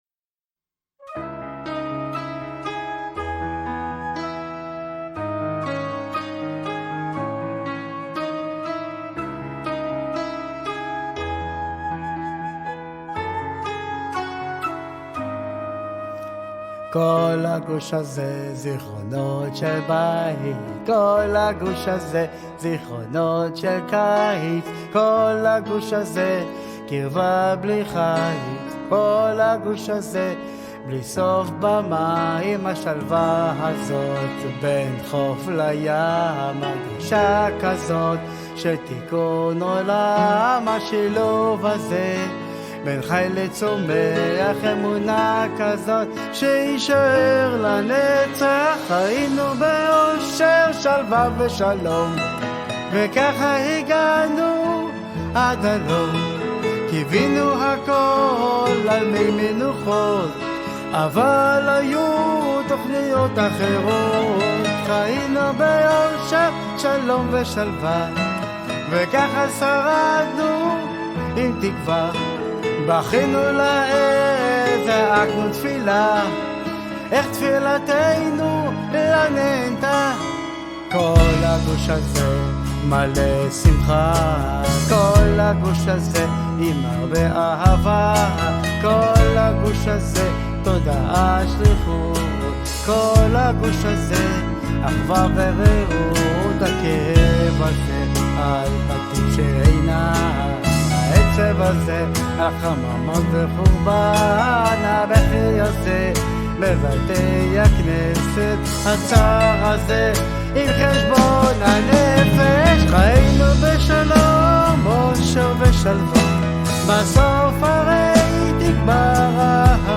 יש בו טעם של פעם שירים ישראליים נוסטלגיים